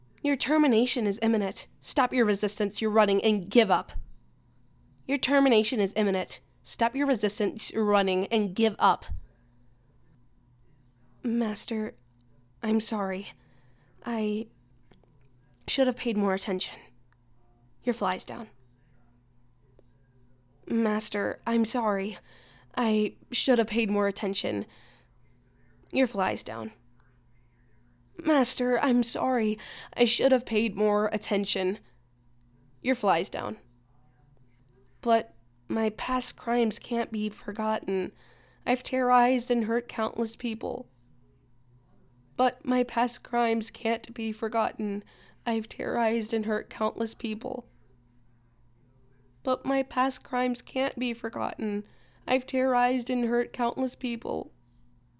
Almost robotic and monotonous.
In the second state, She sounds normal.
She has a mid-low voice.